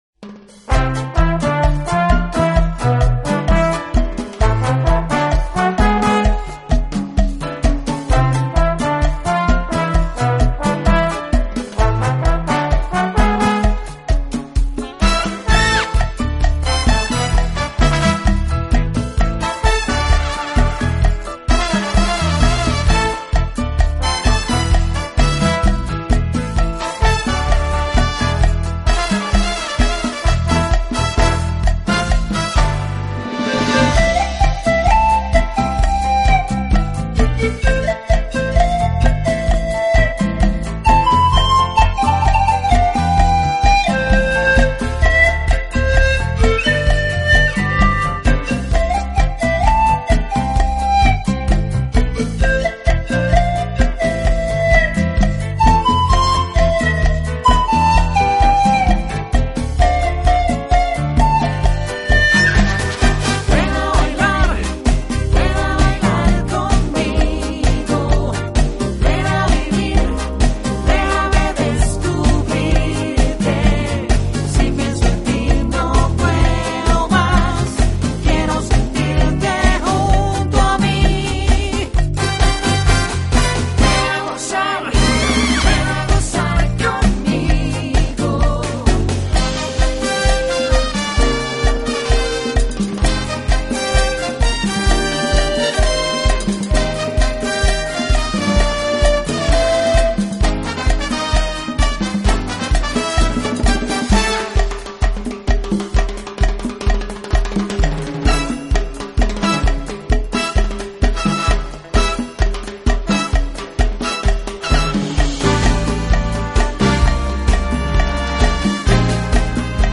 排箫.纯音乐